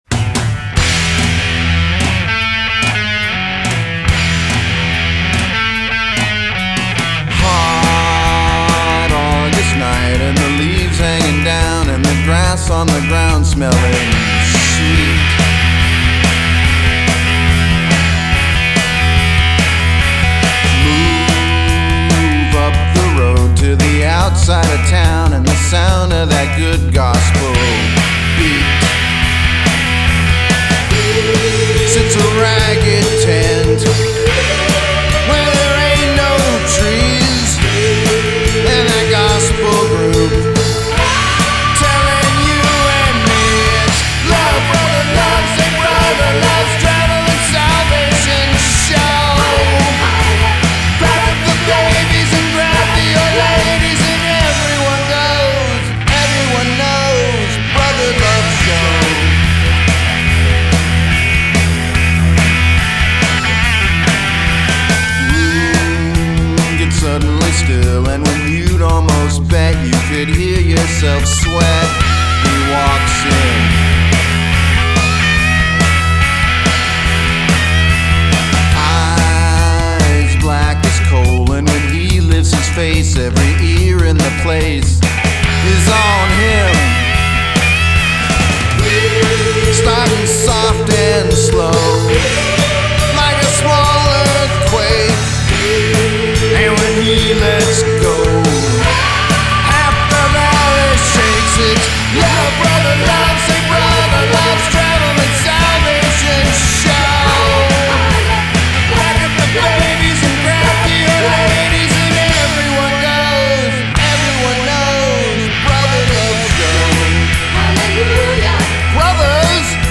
Vocals
Guitars
Bass
Drums
Backing Vocal
Recorded at A to Z Studios